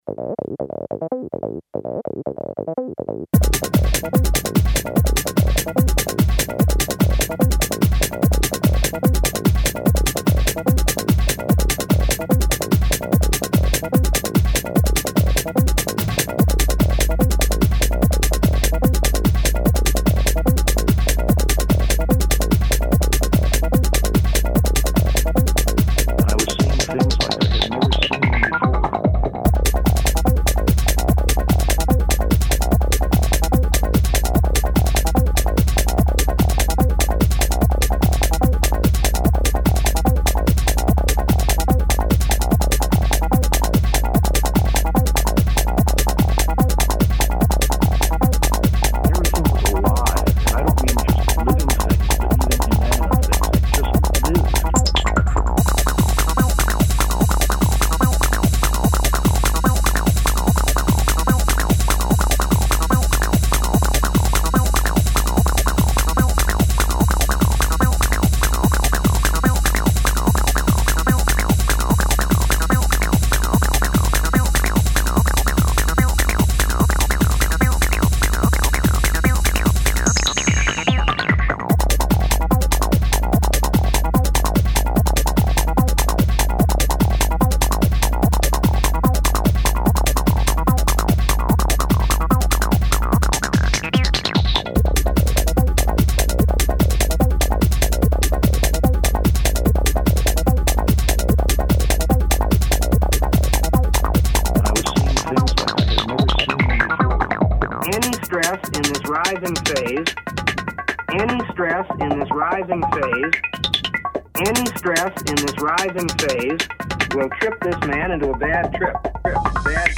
dance/electronic
House
Techno
Leftfield/noise